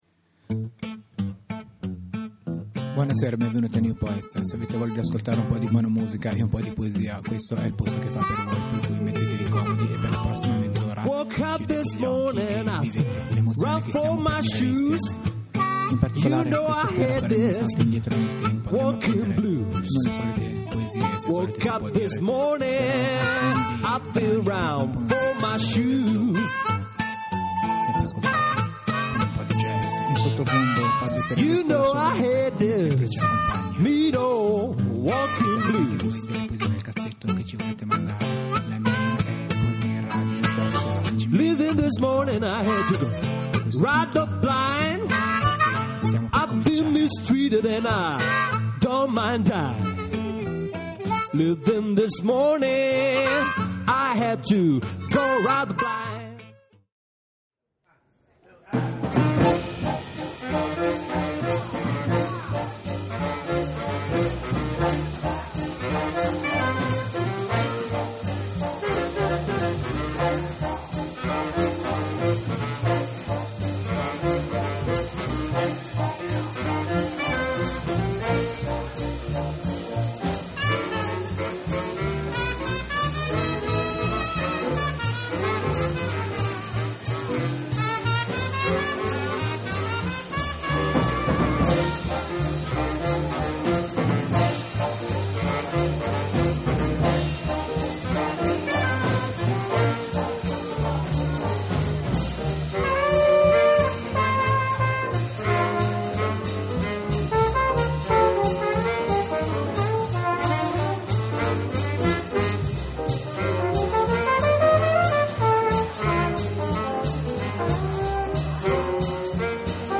Un viaggio nel tempo,nei luoghi: questa puntata di Nu Poets è dedicata ai grandi autori Spagnoli , accompagnata da alcuni dei più famosi standard di musica Jazz..